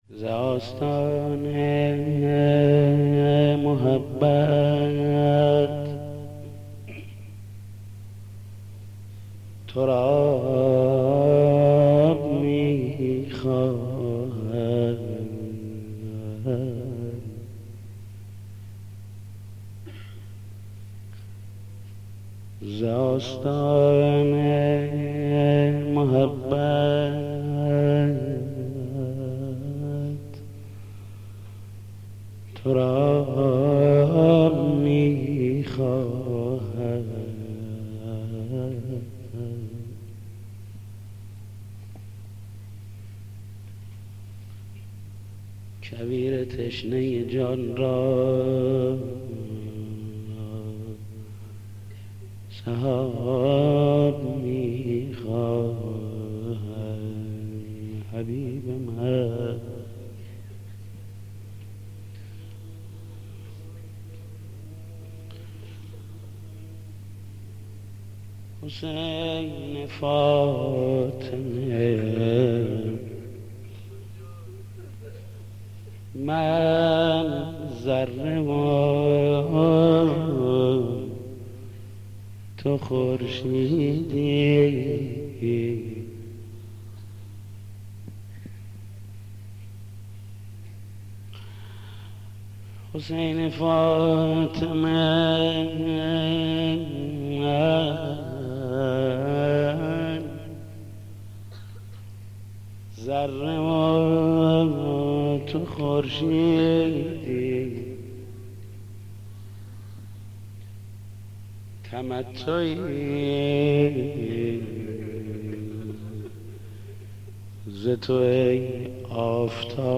مداح
مناسبت : دهه دوم محرم
مداح : محمود کریمی